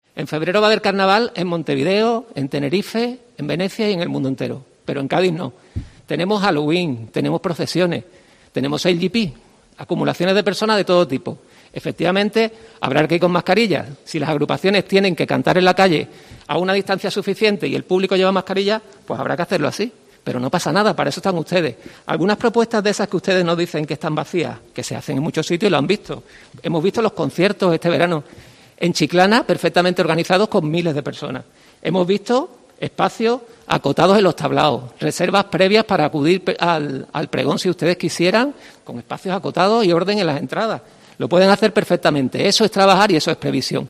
José Manuel Cossi, PP de Cádiz, sobre el festivo de Carnaval